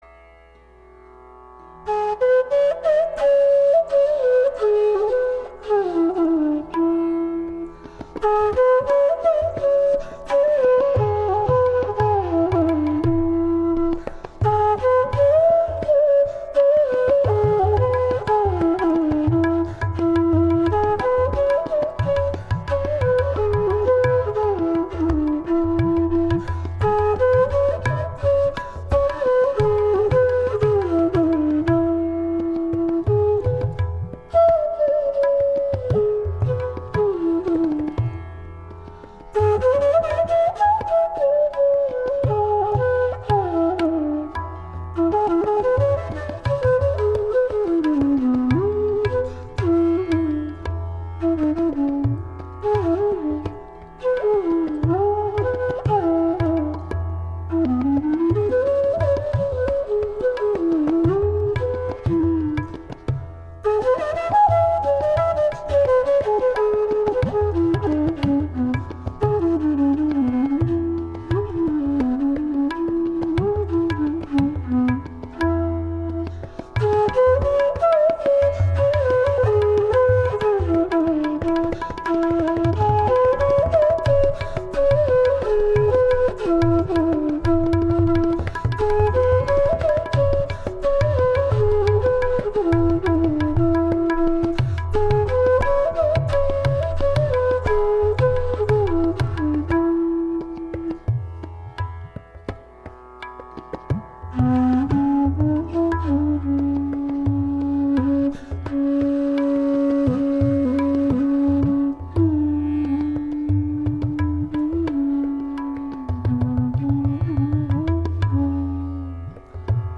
Genre: North Indian Classical.
Gat: Matta Tal (9)   20:30